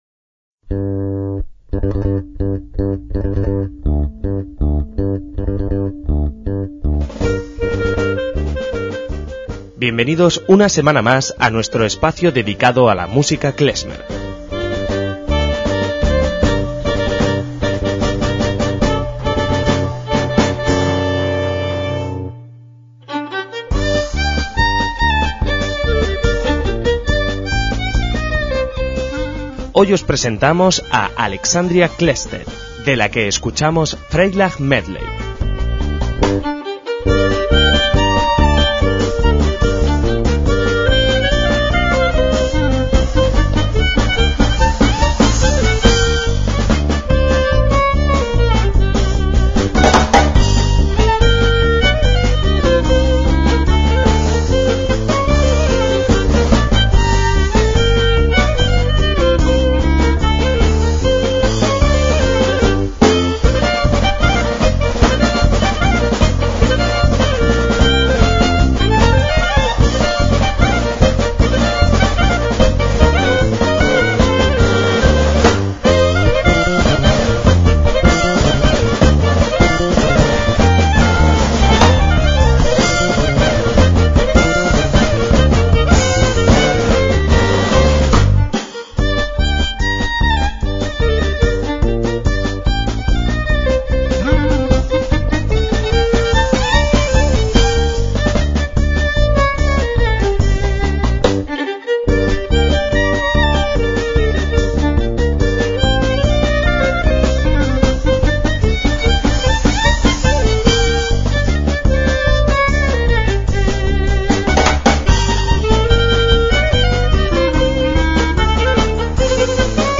MÚSICA KLEZMER
clarinetista
violín
bajo
percusiones